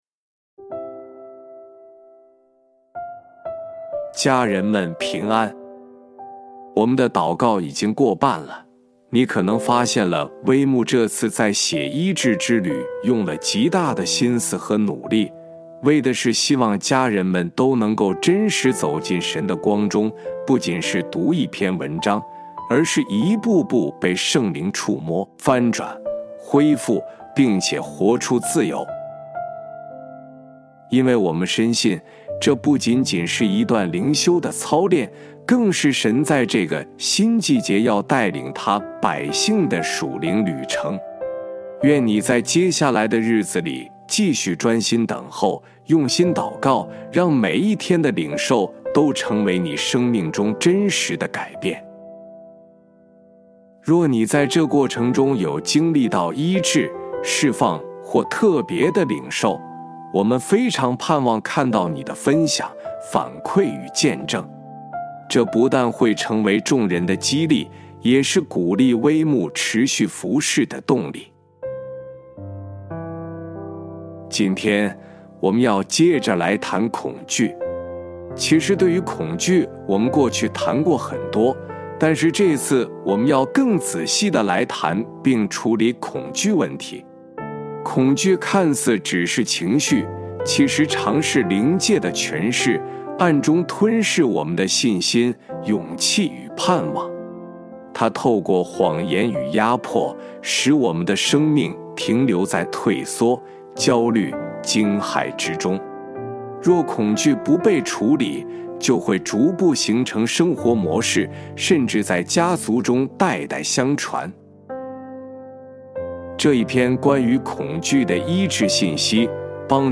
本篇是由微牧之歌撰稿祷告及朗读 第13-14天走出家族的恐惧迷雾，承接信心的产业 第一音源 第二音源 家人们…